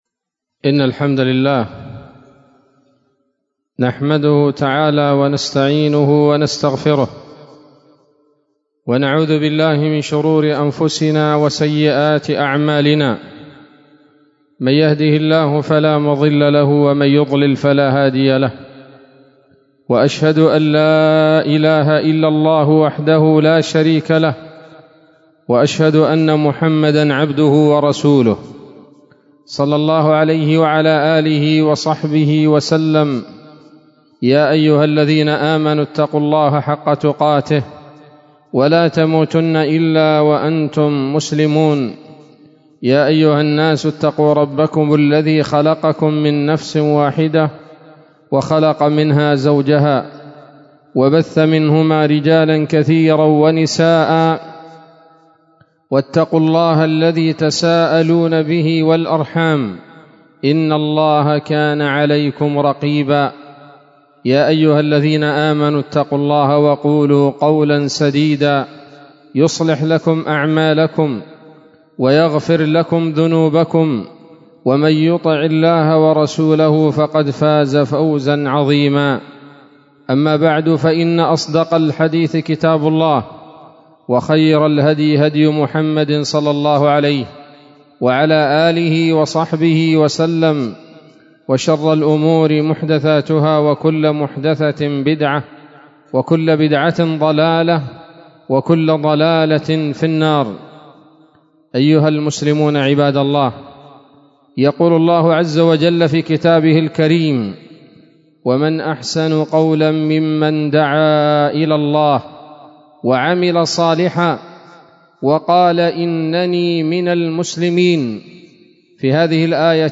خطبة جمعة بعنوان: (( أهمية الدعوة إلى الله )) 11 شعبان 1444 هـ، دار الحديث السلفية بصلاح الدين